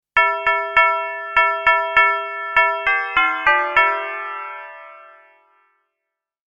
Jingle Bells Doorbell Sound Effect
This door chime plays a recognizable holiday melody. It adds a cheerful, festive sound to your apartment or home.
Jingle-bells-doorbell-sound-effect.mp3